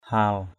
/ha:l/ (t.) hầm, nóng bức = étouffant. hal bluw hL b*~| nực nội = suffoquant. langik hal ndei lz{K hL q] trời nóng bức quá. bel pandiak langik hal bluw...